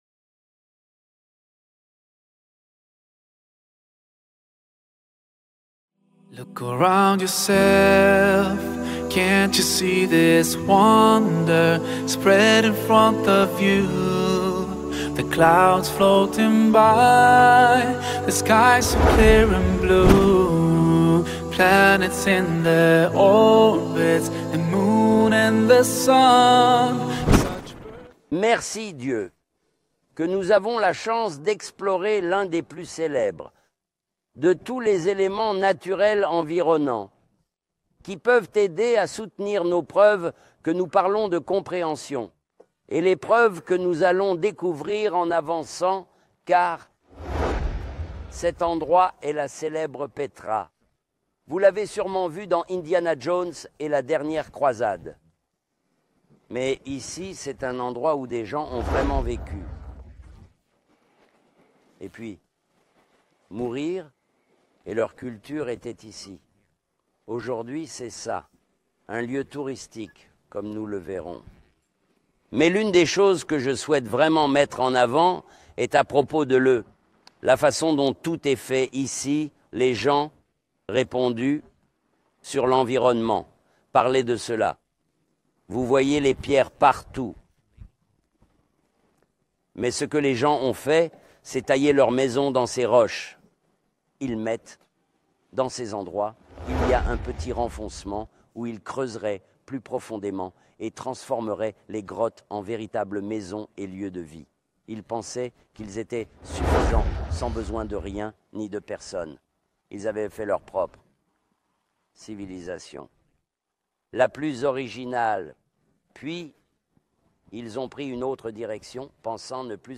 filmée dans le décor de paysages pittoresques et de lieux historiques de Jordanie. Dans cet épisode, il explique le premier pilier de la foi — la croyance en Dieu.